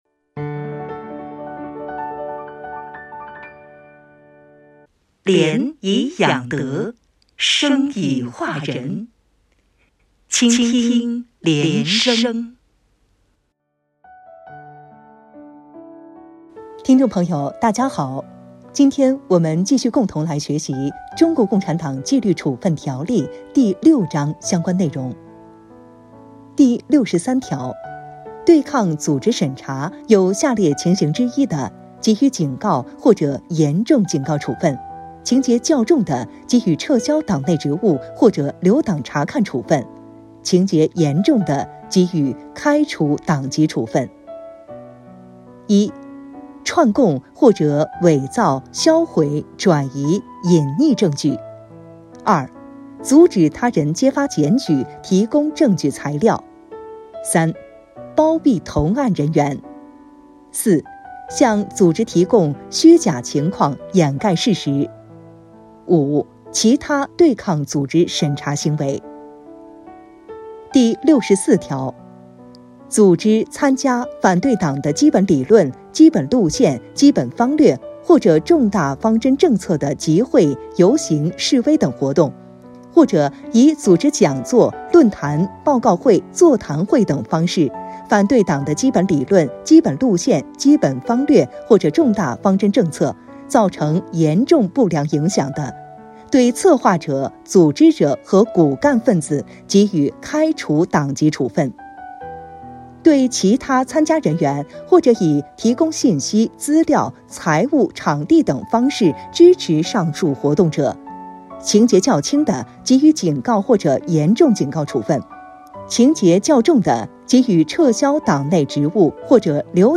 原文诵读系列音频